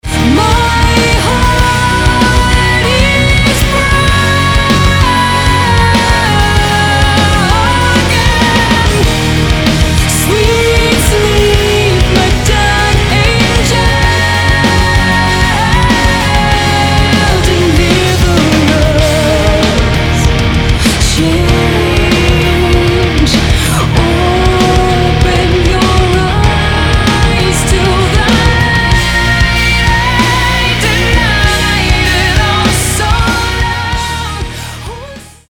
Americká rocková skupina